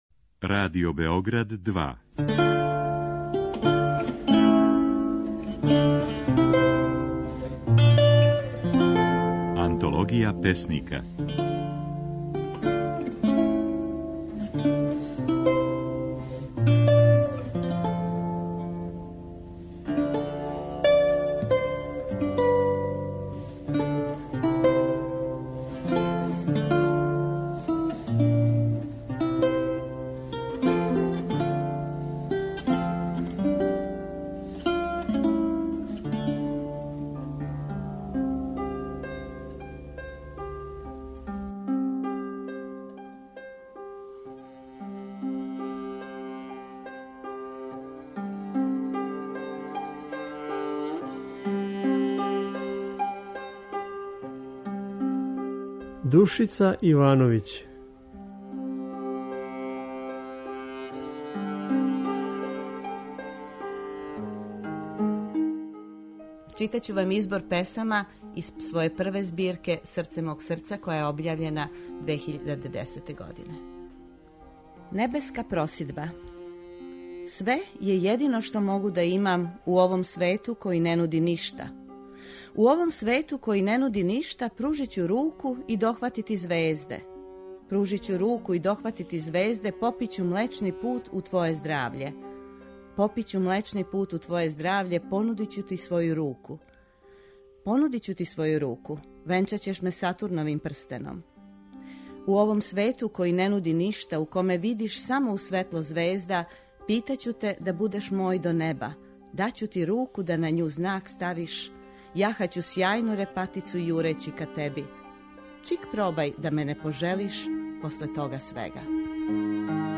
Емитујемо снимке на којима своје стихове говоре наши познати песници